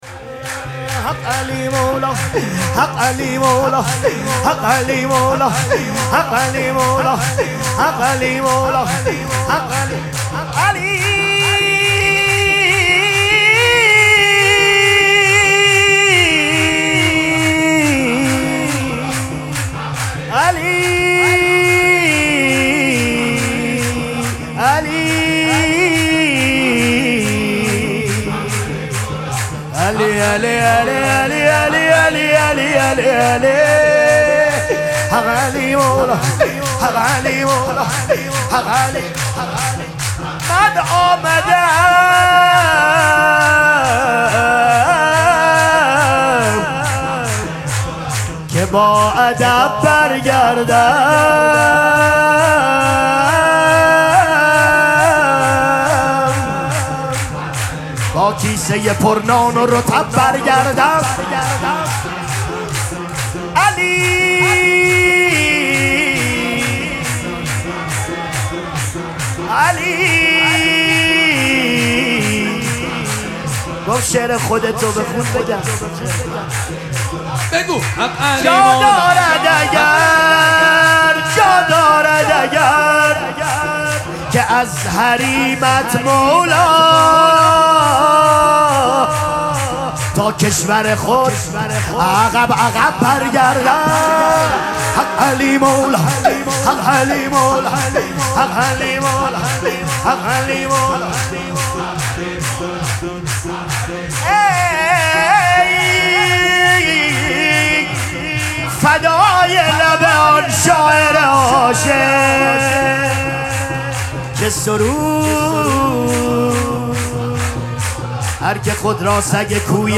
مراسم جشن شام ولادت امام حسن مجتبی(ع)
حسینیه ریحانه الحسین سلام الله علیها
سرود